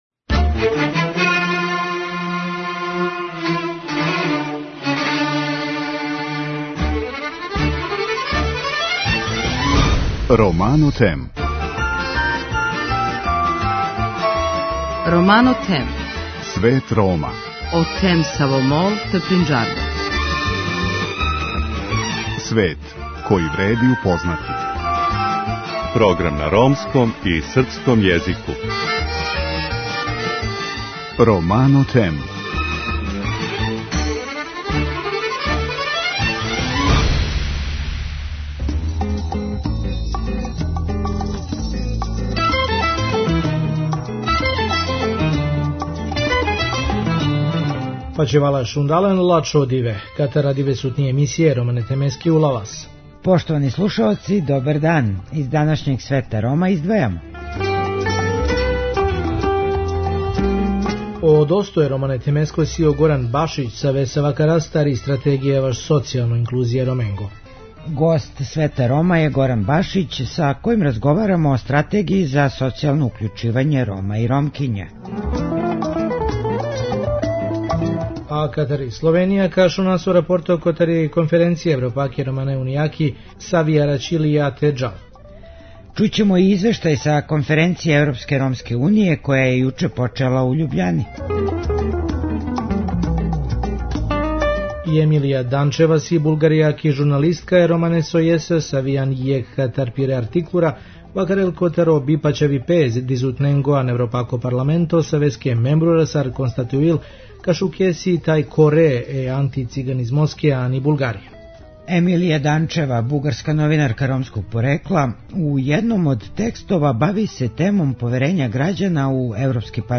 Чућемо и извештај са конференције Европске ромске Уније која је јуче почела у Љубљани. Данас је разговарано о здрављу Рома и Ромкиња и начинима како да им се побољша приступ здравственој заштити.